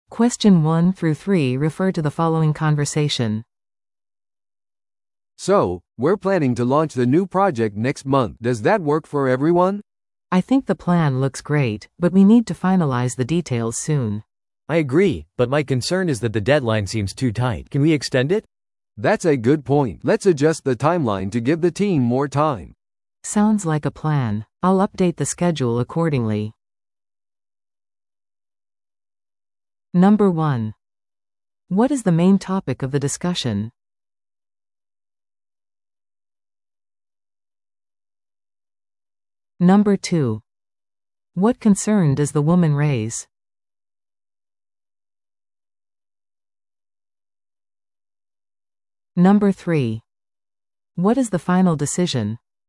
No.2. What concern does the woman raise?